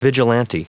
Prononciation du mot vigilante en anglais (fichier audio)